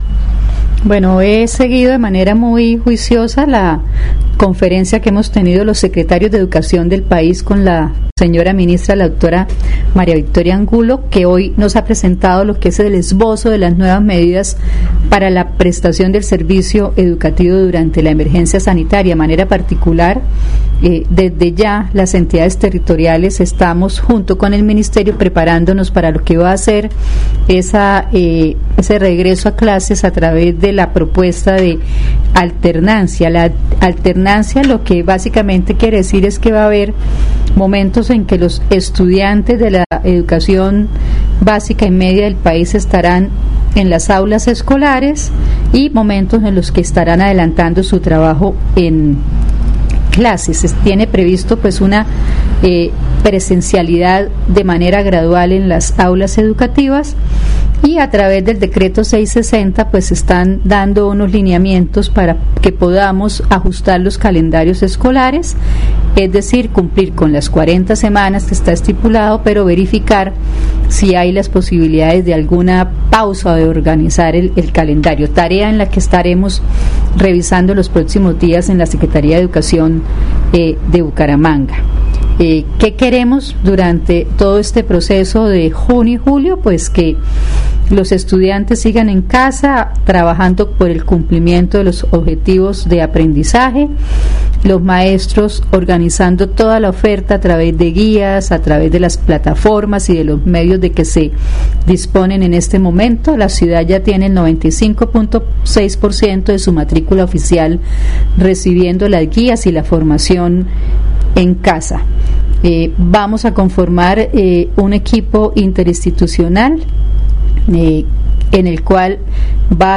Ana Leonor Rueda Vivas, secretaria de Educación Bucaramanga
aNA-leonor-Rueda-secretaria-educacion-caso-encuentro-ministerio-OK.mp3